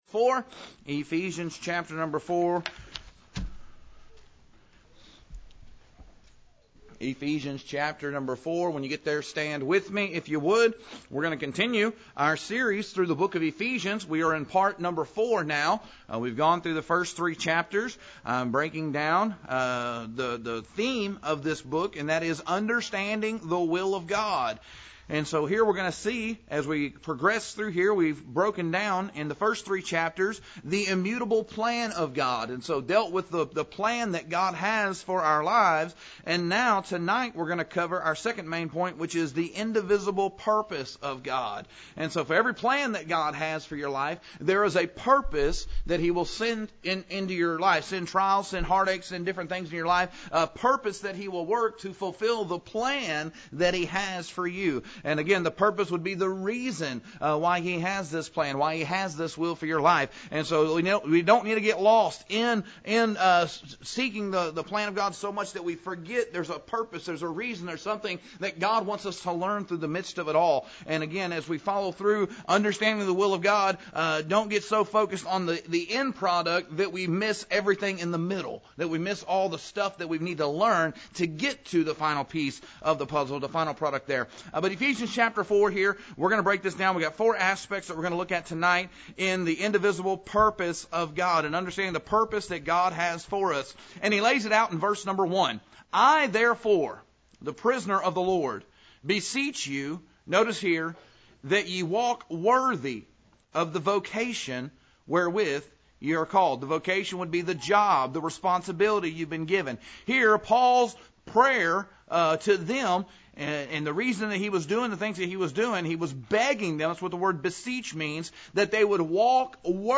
This sermon continues an expositional journey through Ephesians. The focus of this message is the indivisible purpose of God revealed in Ephesians 4: unity.